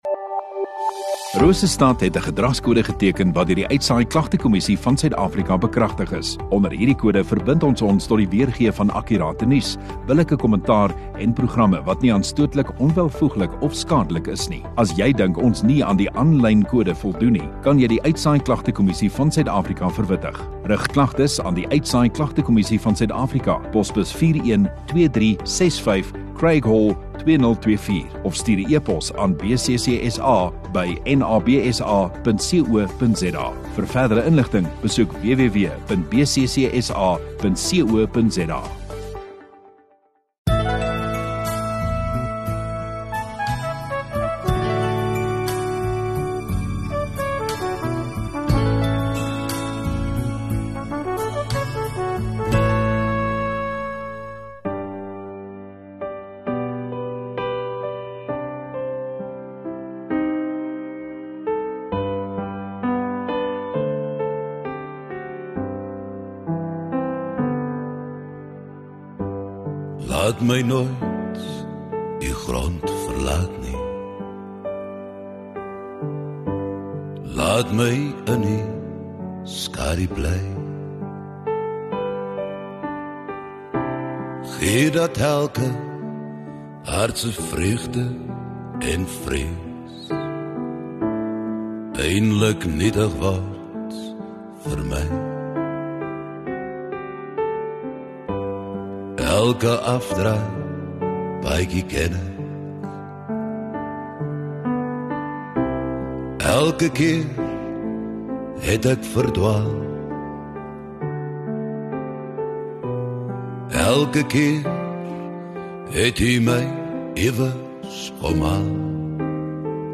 22 Sep Sondagaand Erediens